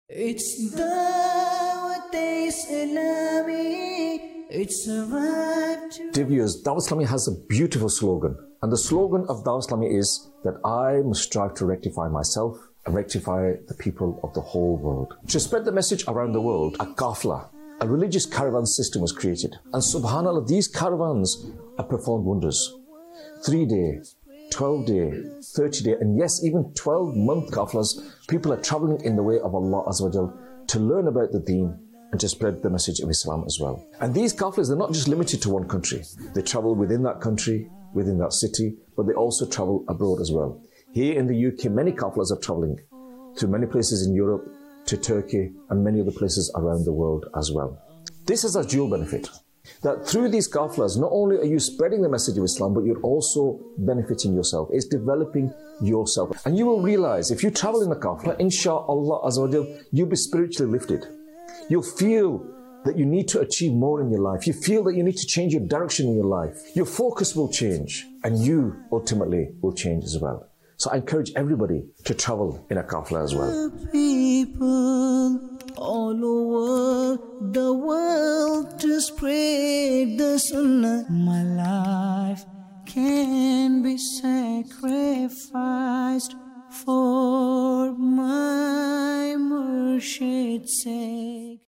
Qafilah | Department of Dawateislami | Documentary 2026